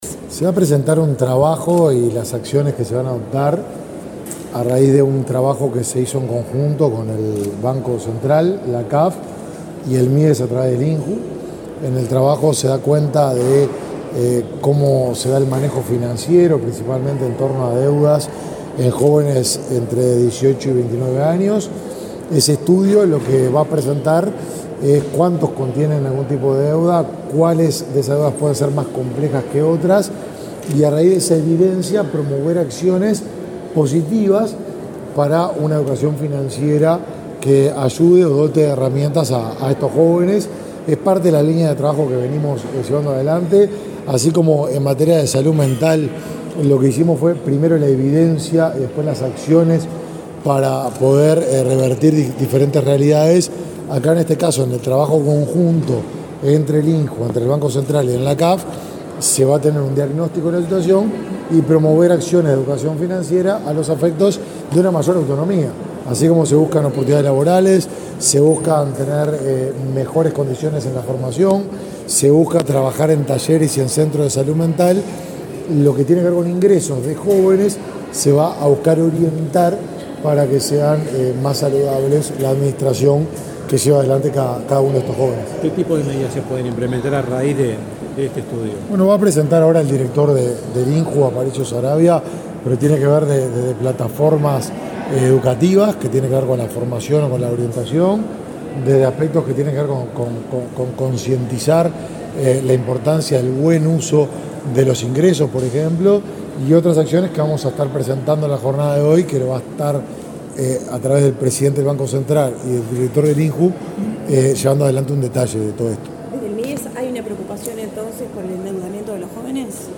Declaraciones del ministro de Desarrollo Social, Martín Lema
El ministro de Desarrollo Social, Martín Lema, dialogó con la prensa, antes de participar, en la Torre Ejecutiva, en la presentación de la línea de